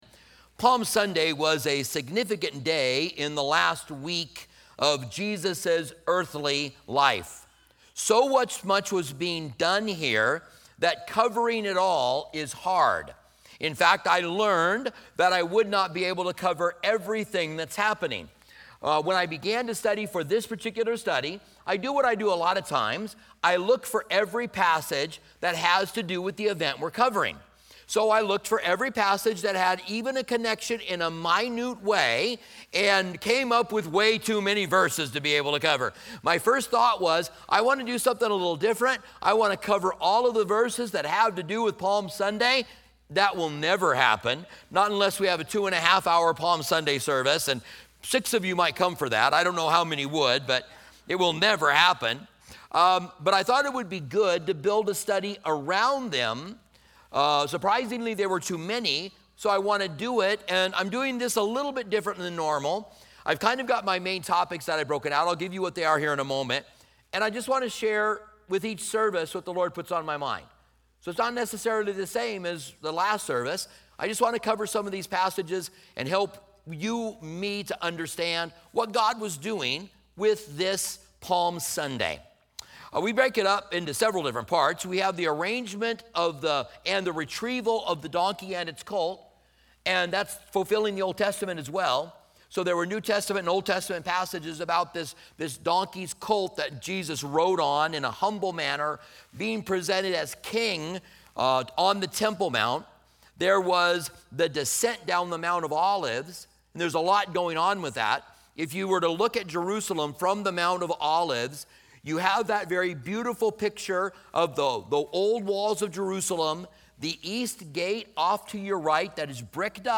29:54 Closing prayer